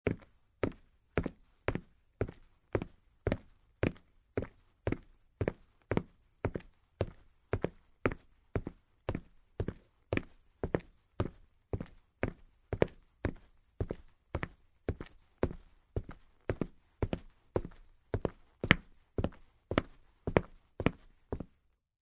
在较硬的路面上行走－YS070525.mp3
通用动作/01人物/01移动状态/01硬地面/在较硬的路面上行走－YS070525.mp3
• 声道 立體聲 (2ch)